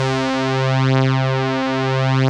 Index of /90_sSampleCDs/Club-50 - Foundations Roland/SYN_xAna Syns 1/SYN_xJX Brass X2